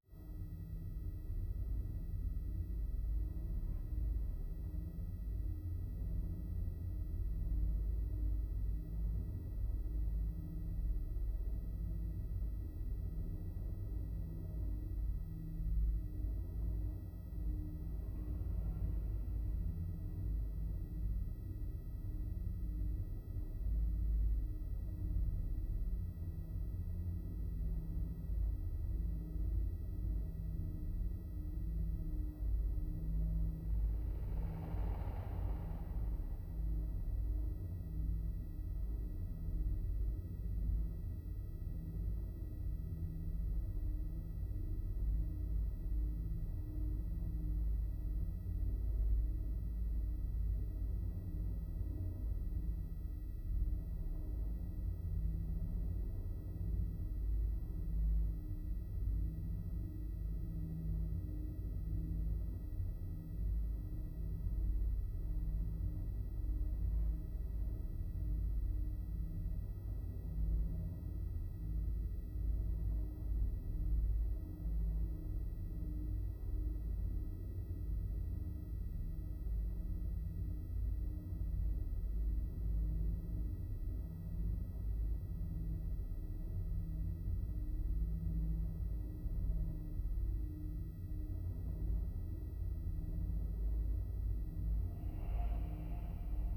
free horror ambience 2
ha-undercurrent.wav